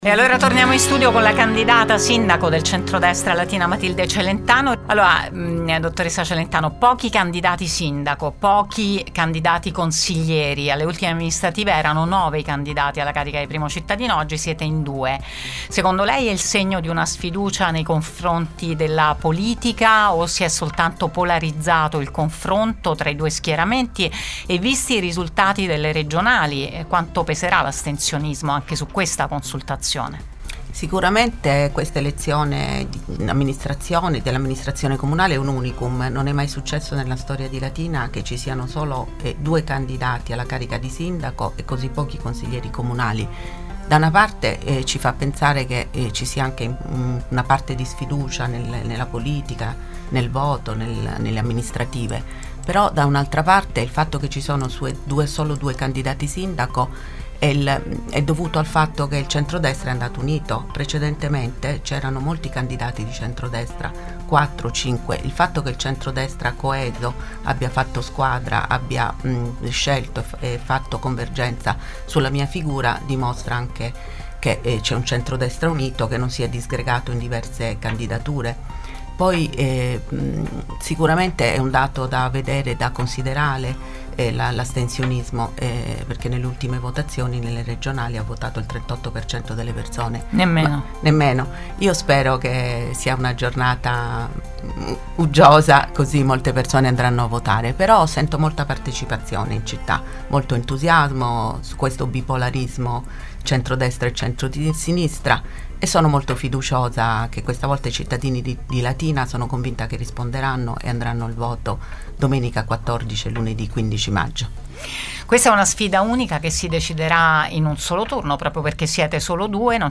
“Un onore essere stata candidata, ma avverto anche l’onere e la grande responsabilità verso la mia città”, ha detto intervenendo in diretta su Radio Immagine e su Immagine Tv (canale 212 del digitale terrestre) in apertura di un’agenda fittissima di impegni.